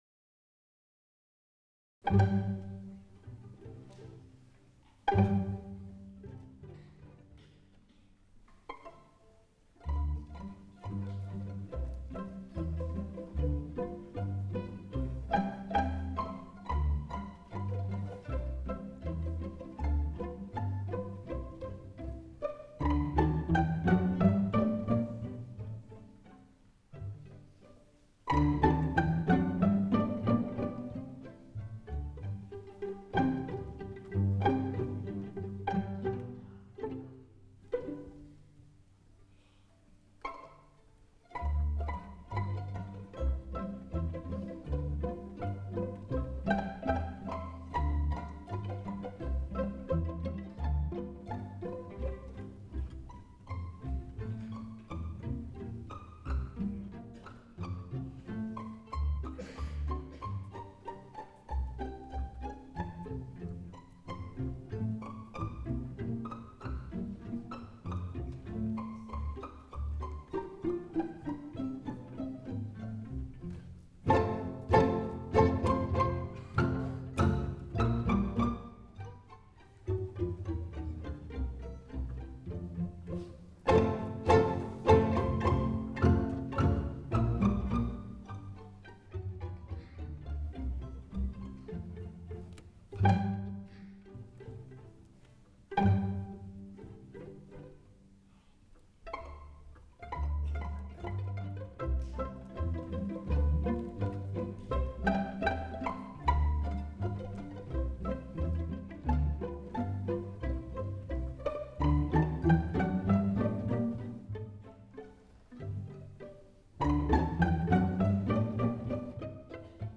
Concert du nouvel an 2026
(Wav stéréo 48Khz 24Bits non compressé, nécessite un haut-débit)